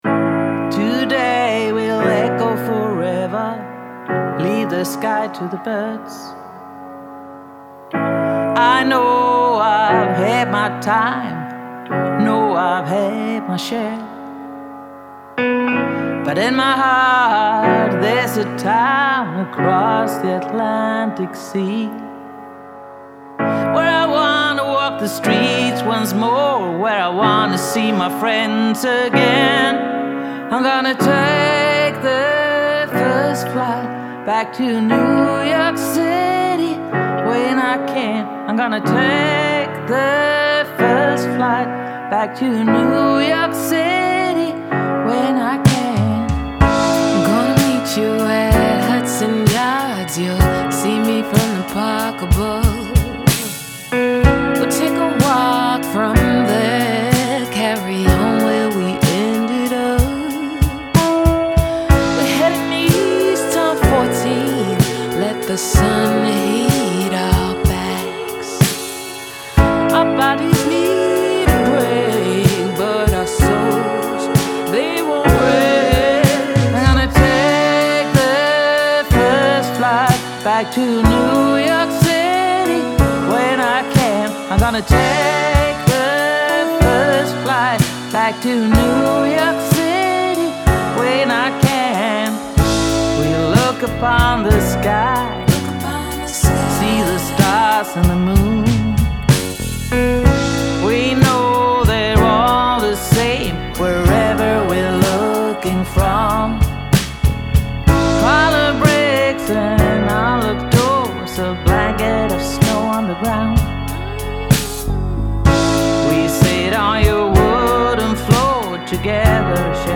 Singer/Songwritern
kompetent och trivsam americana